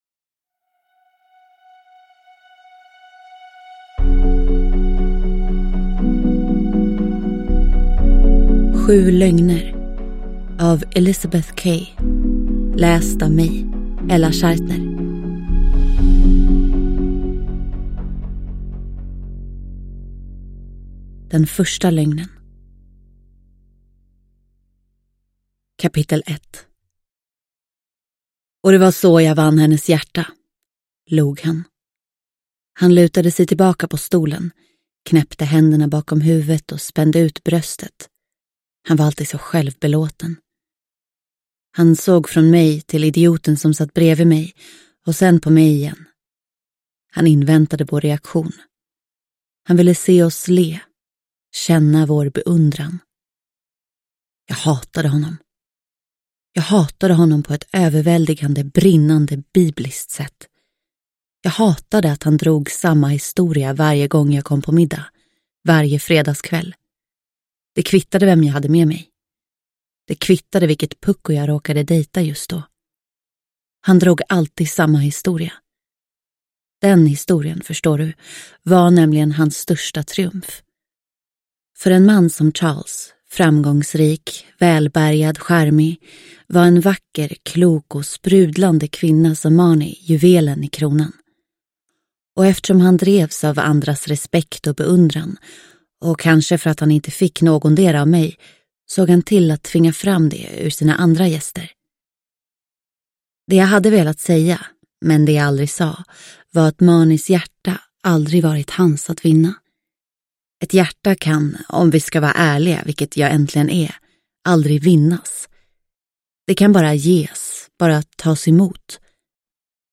Sju lögner – Ljudbok – Laddas ner